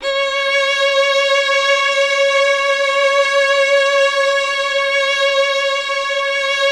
MELLOTRON.10.wav